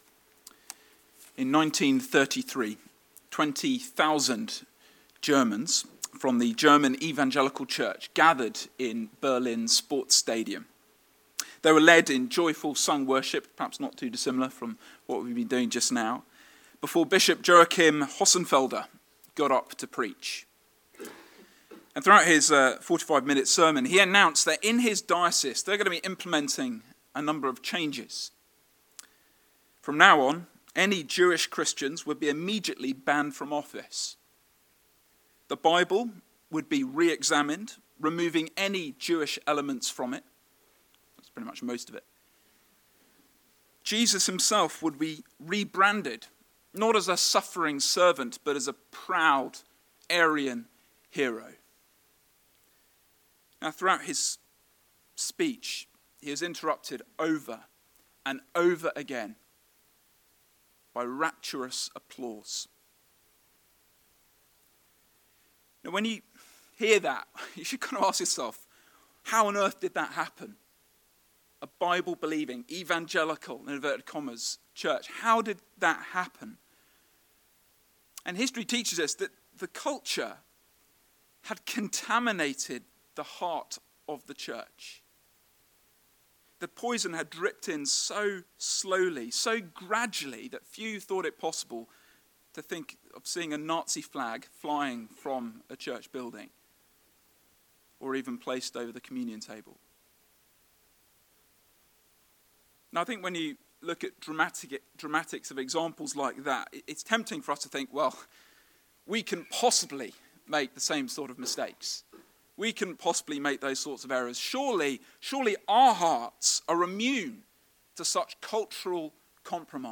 This is the seventh sermon in our Weakness series looking at 2 Corinthians.